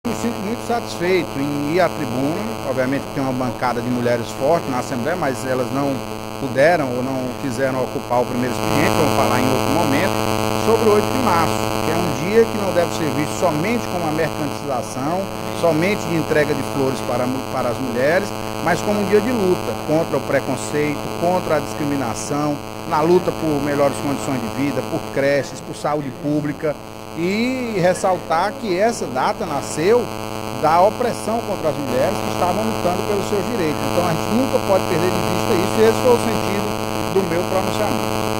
No primeiro expediente da sessão plenária da Assembleia Legislativa desta sexta-feira (07/03), o deputado Antonio Carlos (PT) parabenizou as mulheres pelo Dia da Mulher, a ser celebrado no sábado (08/03).